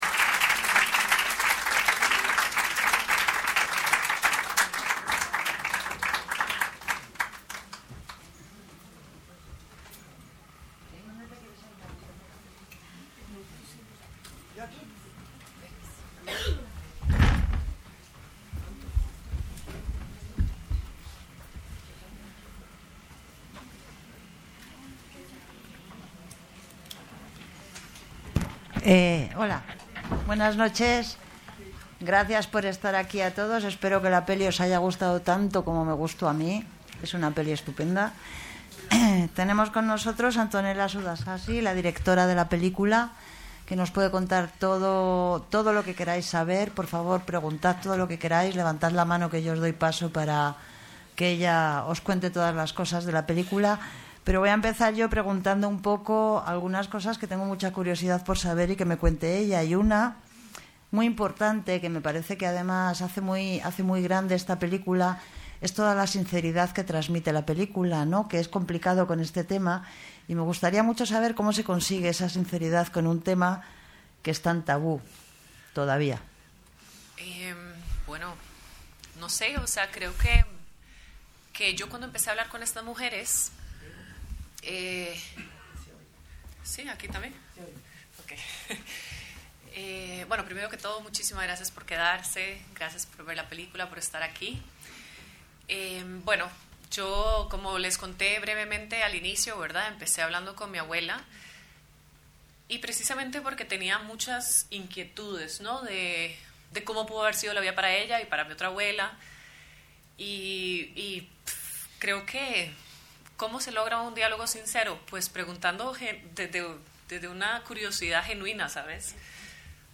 Encuentro organizado por Casa de América, Substance Films y PlayLab Films.